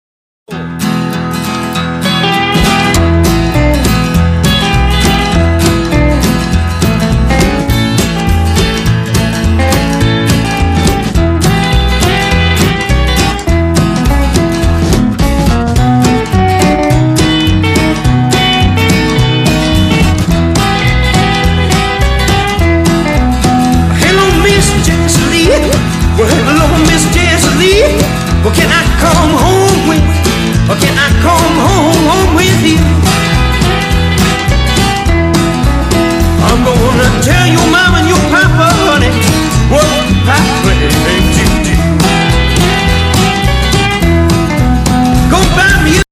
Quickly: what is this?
Genre: Rockabilly